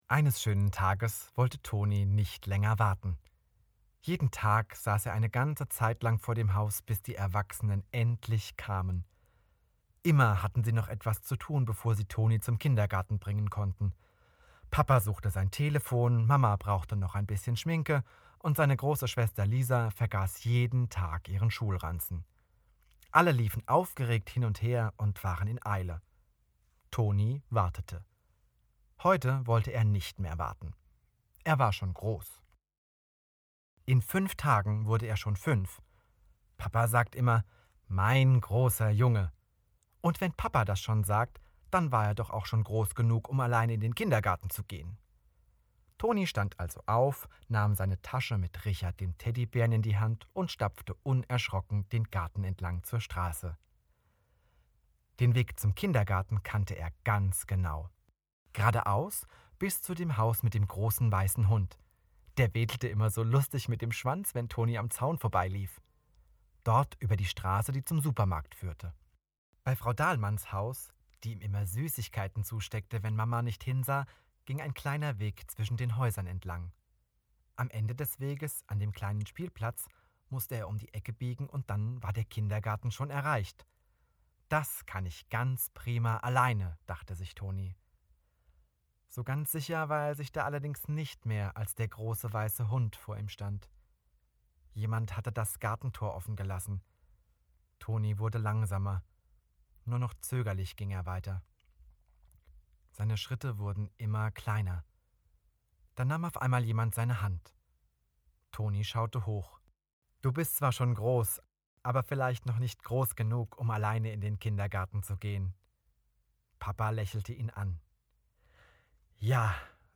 Sprecher & Moderator
Kurzgeschichte